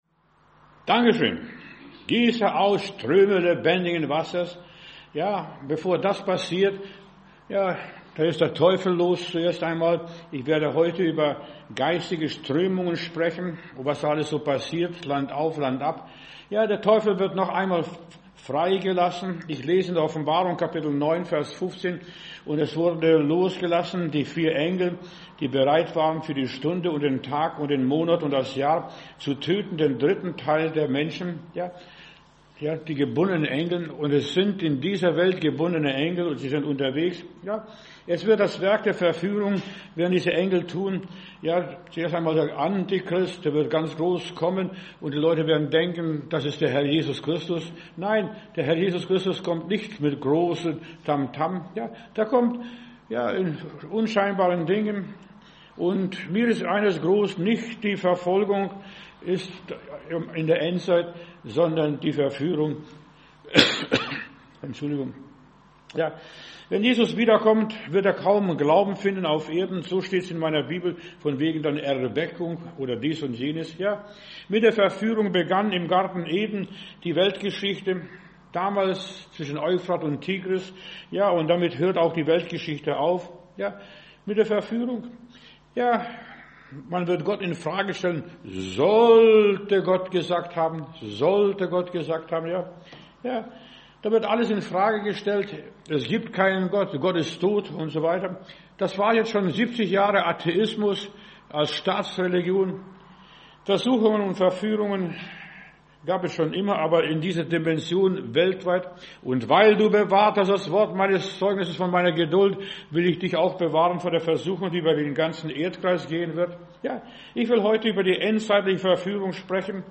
Predigt herunterladen: Audio 2025-11-15 Geistige Strömungen Video Geistige Strömungen